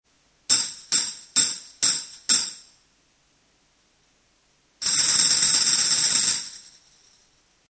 LP Jingle sticks 6 small cymbals